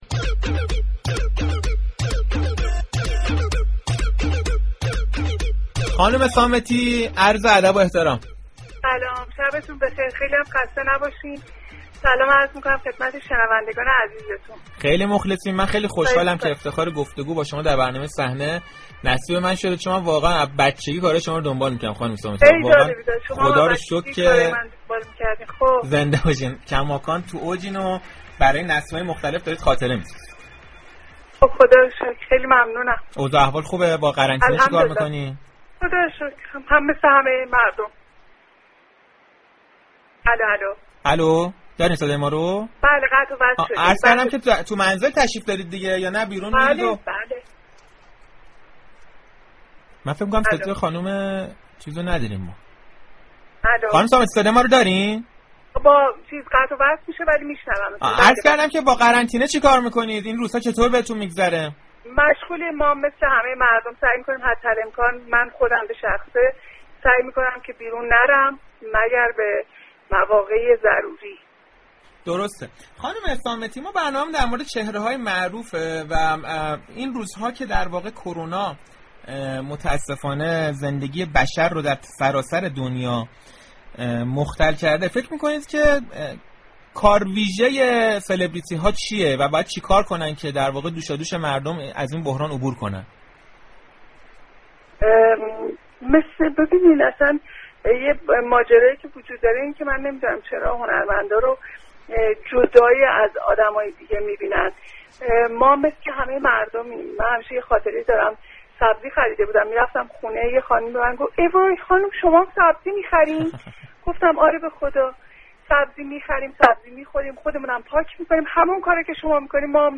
الان هم از خانه با مخاطبان عزیز رادیو تهران صحبت می‌كنم.
وی در پاسخ به سوال مجری برنامه در مورد نقش سلبریتی‌ها در كمك به گذران بحران‌هایی نظیر بحران اخیر كشور، گفت: اخباری كه در ارتباط با كمك‌های میلیونی سلبریتی‌های اروپایی و آمریكایی می‌شنویم، نمی‌تواند برای چهره‌های ایرانی هم گفته شود.